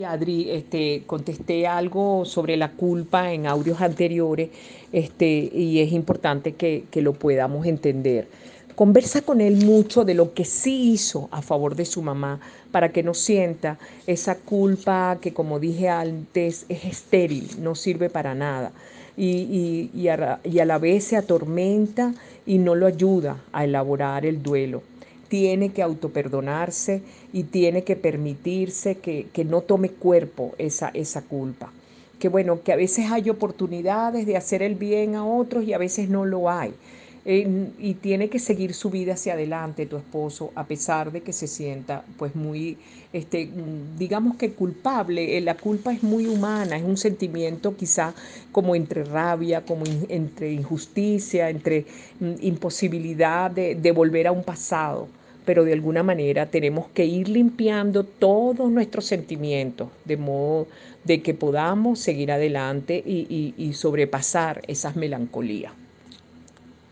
Ronda de preguntas